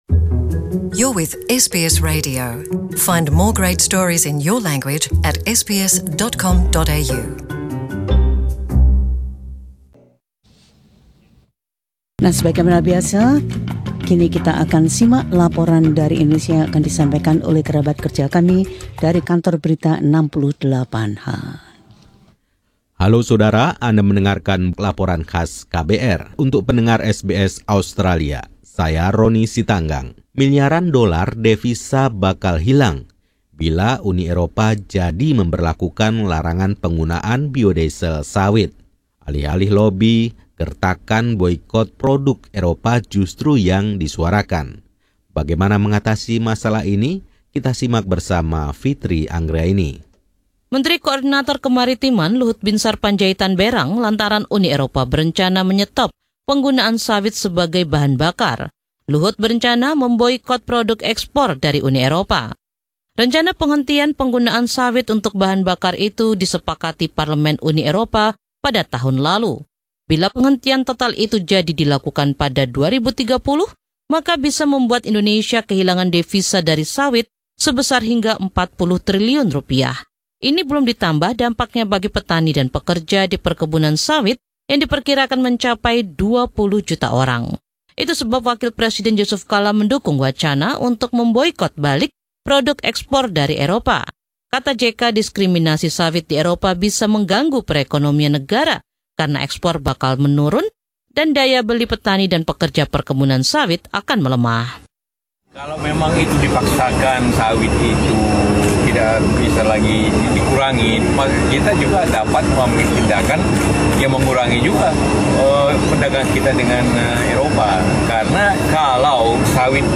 Laporan KBR 68H: Eropa dan Bio Diesel Sawit.